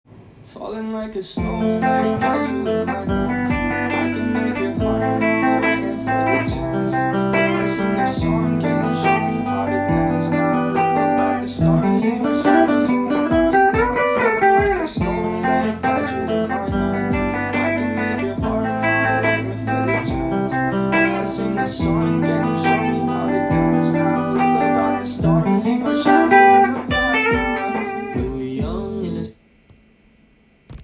音源に合わせて譜面通り弾いてみました
このように５つのコードの繰り返しになります。
レッチリのジョンフルシアンテ風にアルペジオを
入れて、更にペンタトニックスケールを使って
フレーズもちりばめてみました。
ハンマリングやプリングが入ってくるので小指を